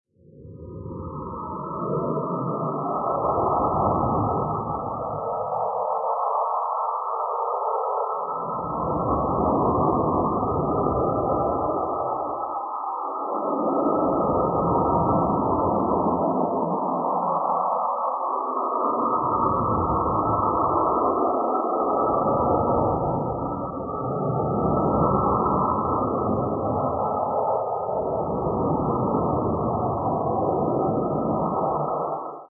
巨大的抽象昆虫
描述：来自另一个星球的怪异昆虫互相交谈。害怕！通过计算机合成生成样本
标签： 怪异 无人驾驶飞机 令人毛骨悚然 邪恶 吓人 黑暗 效果 错误 恐怖 FX 怪物 噪声 生物 空间 科幻 环境 外星人 变态 昆虫 大气
声道立体声